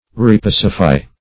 Repacify \Re*pac"i*fy\ (r?-p?s"?-f?), v. t. To pacify again.